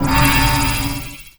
Magic_SpellShield05.wav